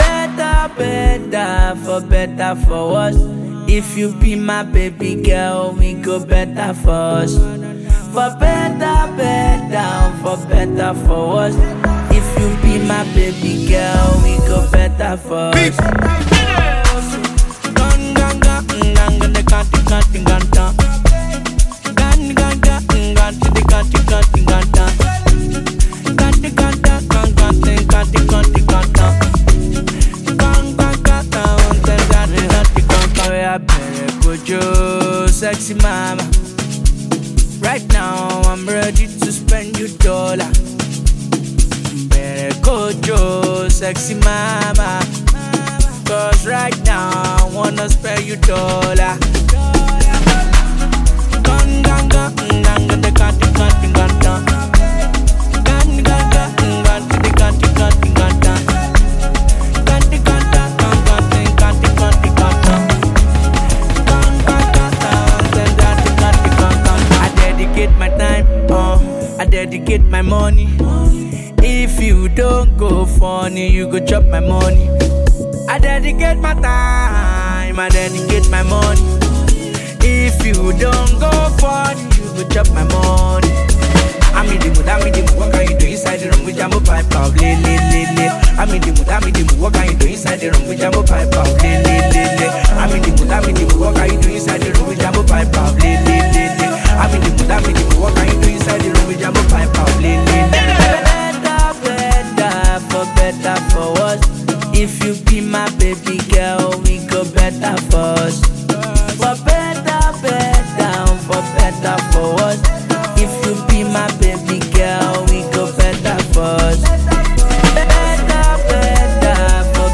Amapiano influenced single